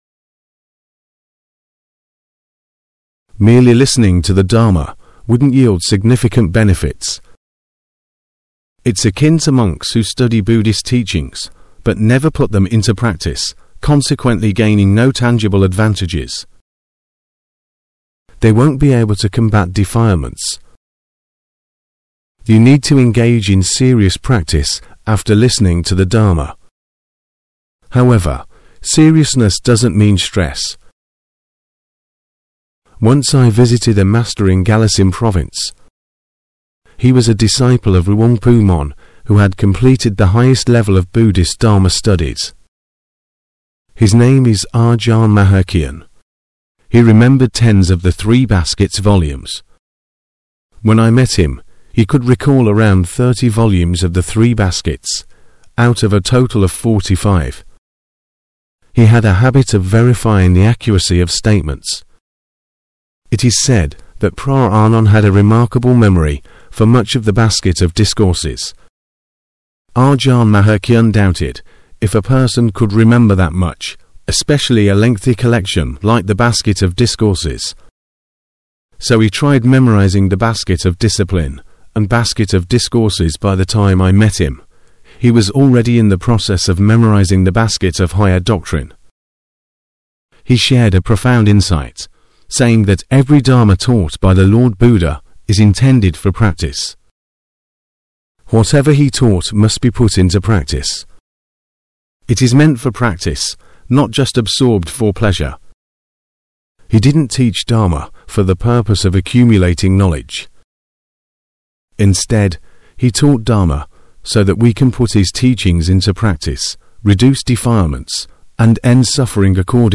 Edited and narrated - Practice Until it Becomes Automatic - 20 May 2023